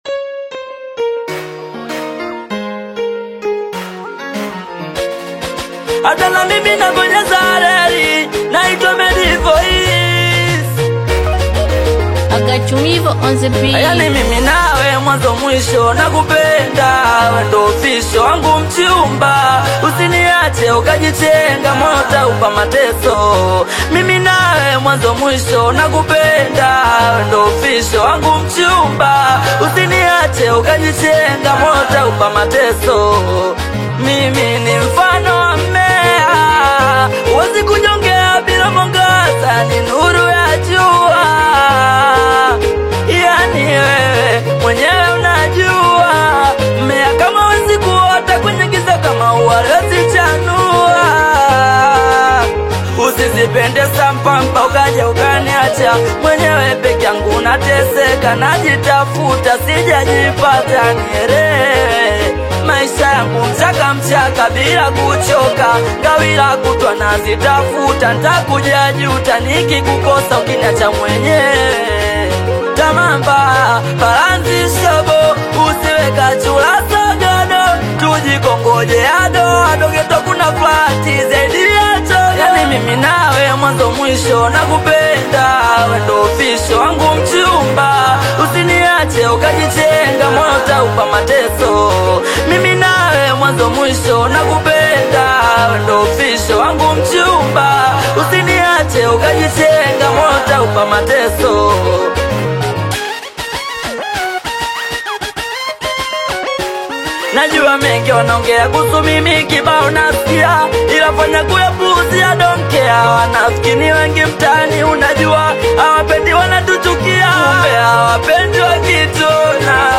AudioSingeli
romantic Singeli track
warm vocals and expressive emotion over melodic rhythms
soulful ode to partnership and affection